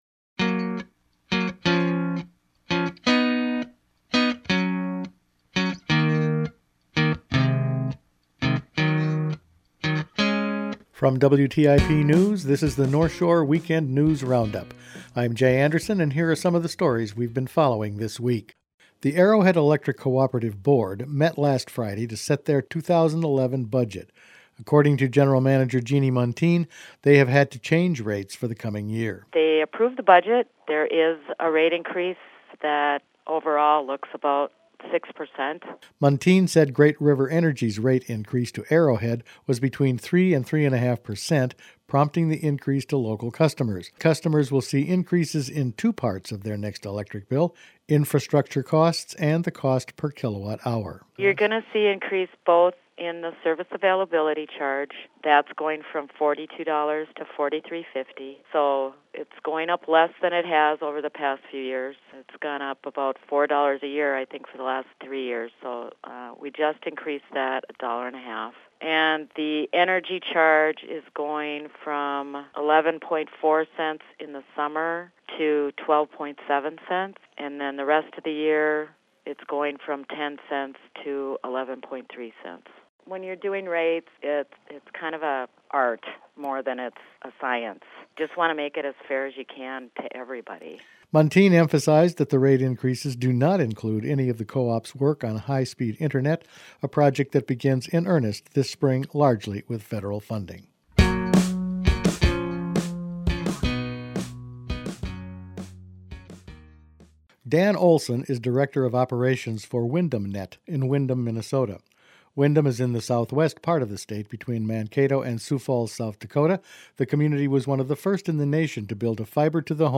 Weekend News Roundup for Feb. 5